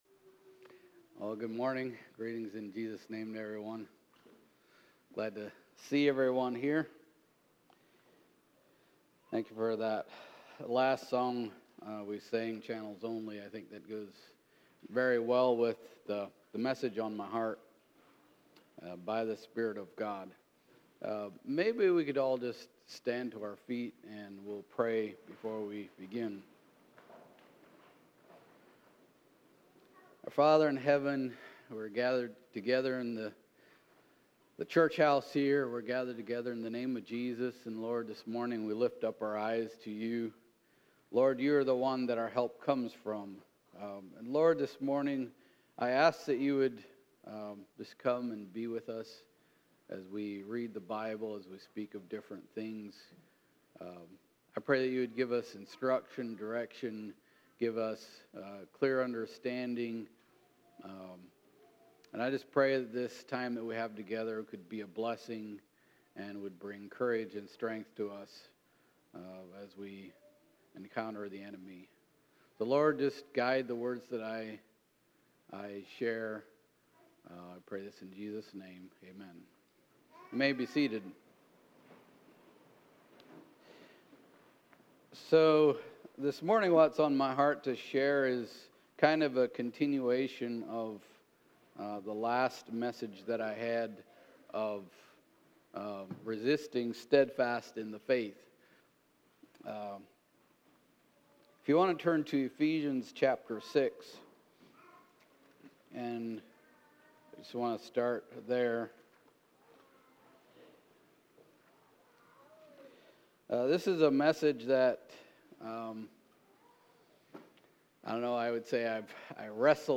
CD995 A sermon about overcoming evil spirits with the powerful spirit of God!